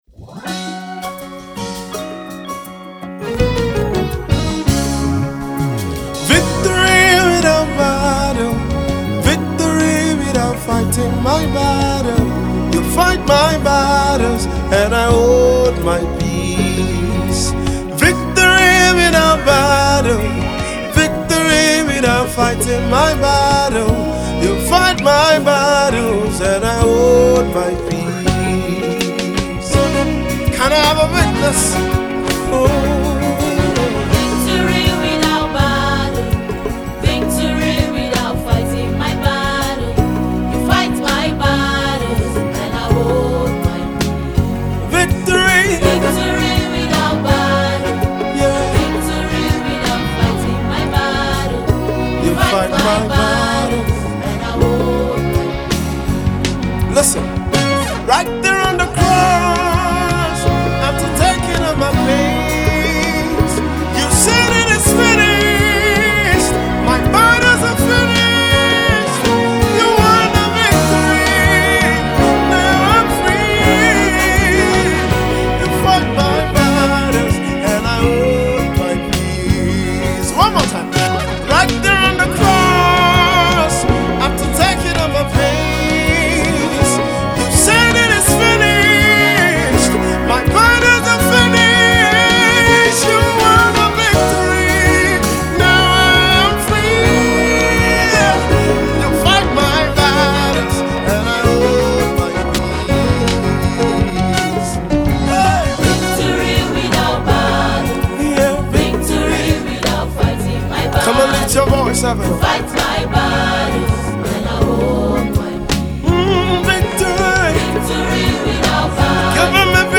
Nigerian Prolific Gospel Music minister
storming single